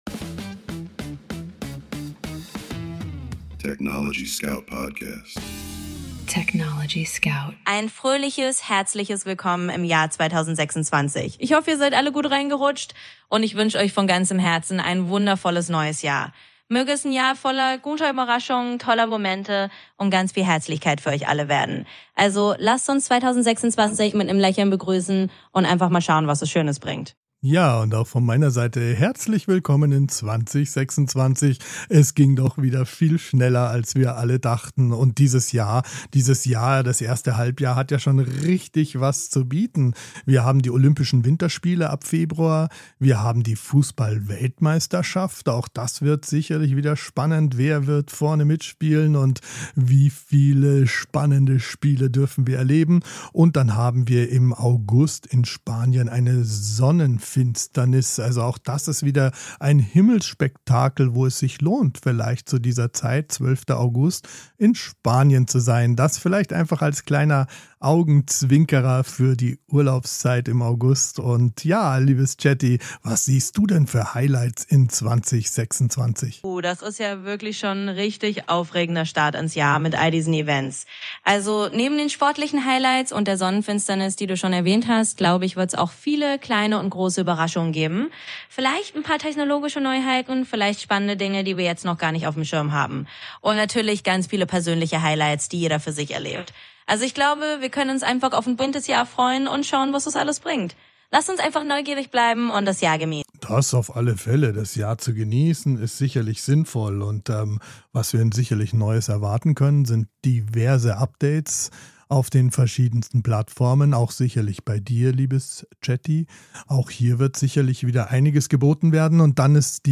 Mensch und KI sprechen miteinander – nicht